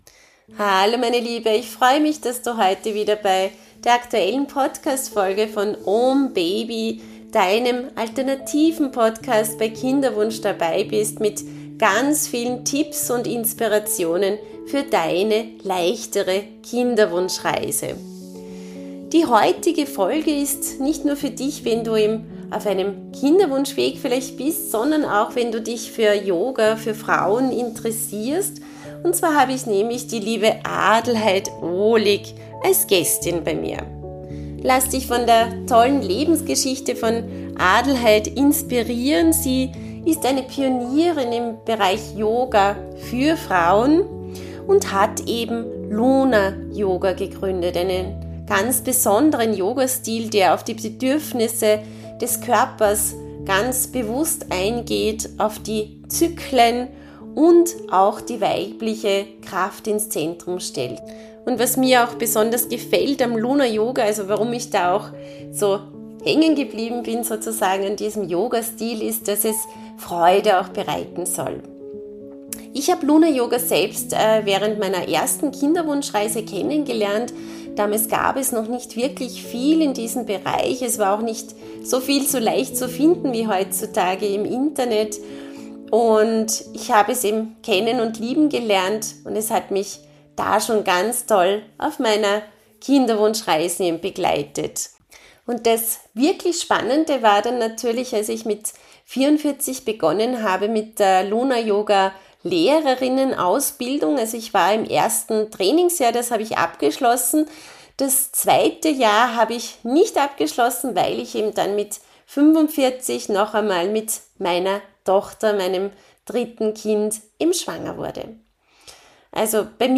Dieses Gespräch ist warm, ehrlich, weise – und ein Geschenk für jede Frau, die spürt: Da ist noch mehr möglich.